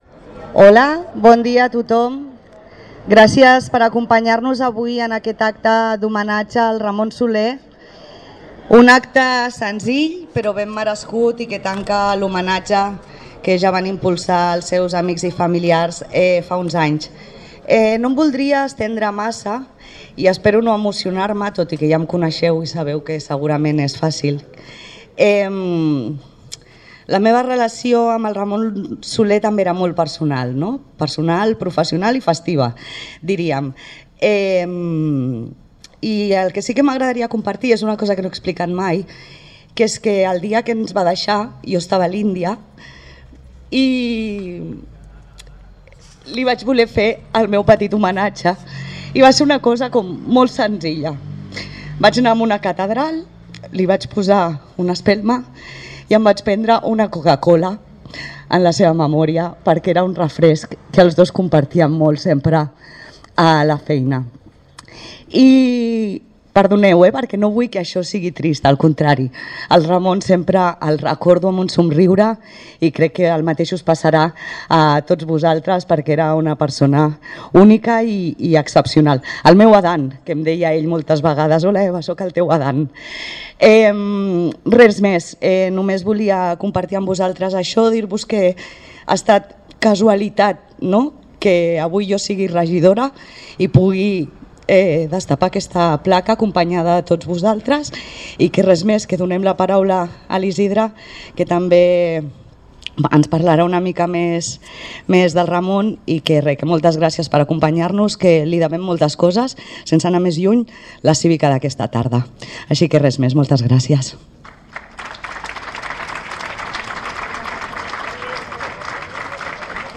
En aquest nou espai es vol que siguin protagonistes les persones que, d’una manera o d’una altra, hagin estat protagonistes per les seves accions voluntàries en favor de Sitges i de la seva cultura popular. Escoltareu, per aquest ordre, la regidora de festes, Eva Martín